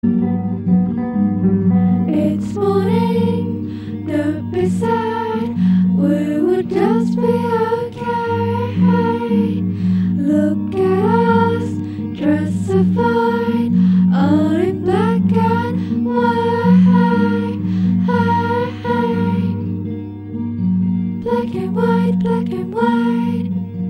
Indie-pop/rock/experimenta/lo-fi project